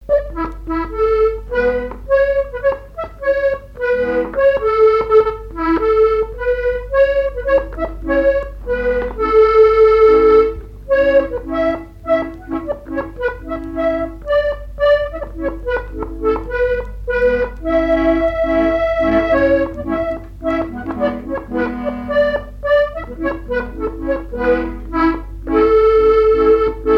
Fonction d'après l'analyste gestuel : à marcher ;
Genre laisse
Pièce musicale inédite